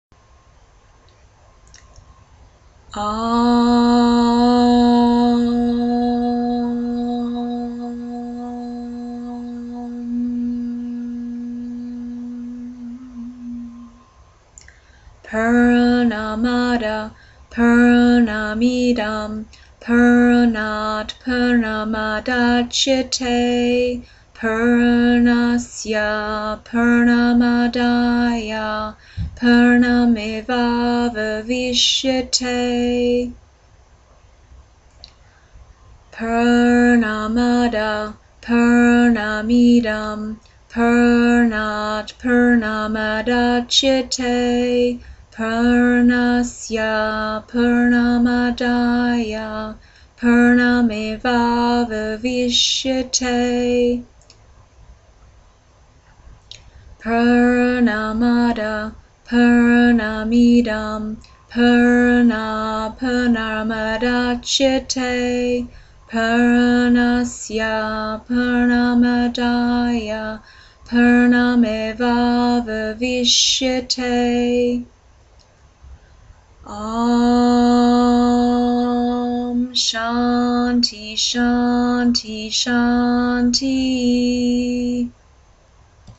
Mantra
The passages that we chant at yogaphysio are short passages taken from ancient philosophical texts called the Upanishads.